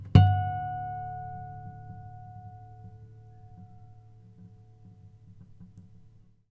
harmonic-07.wav